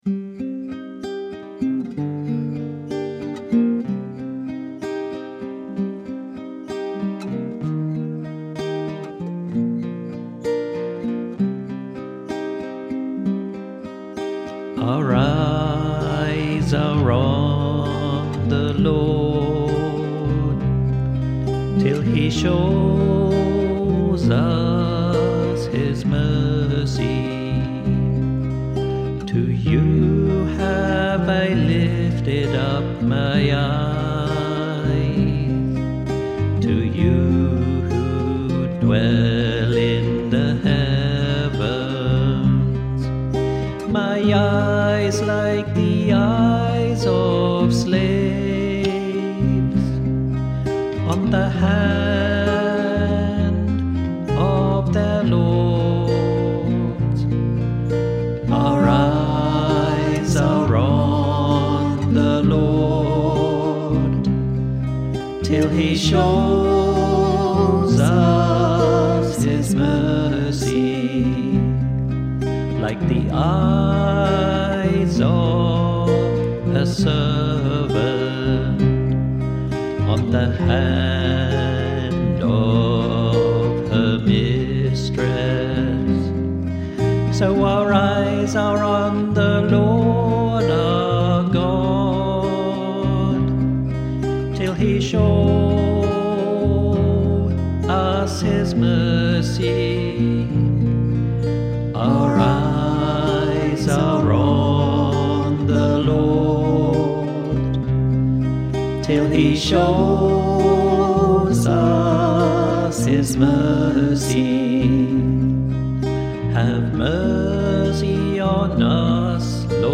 Music by the Choir of Our Lady of the Rosary RC Church, Verdun, St. John, Barbados.